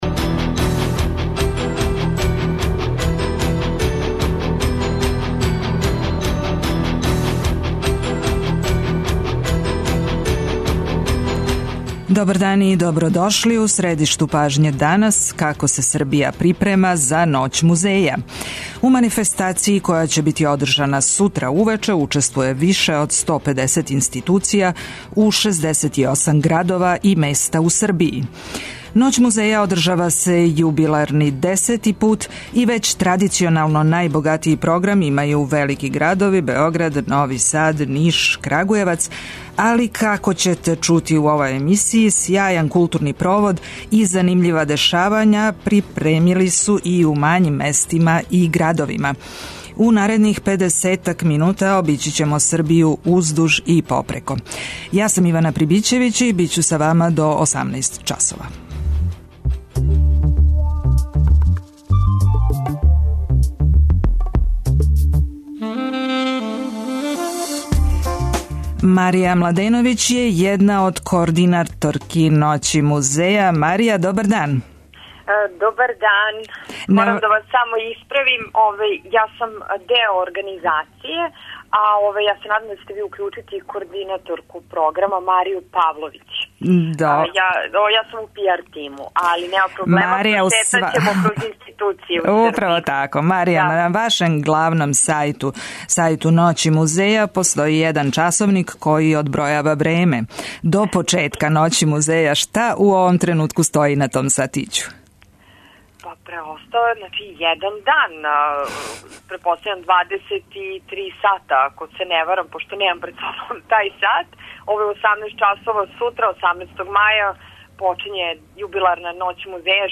Саговорници емисије су кустоси музеја, етнолози, историчари, организатори изложби и осталих дешавања из Шабца, Свилајнца, Бора, Беле Цркве, Трстеника, Новог Пазара, Параћина, Бачке Тополе, Аранђеловца и Пожеге.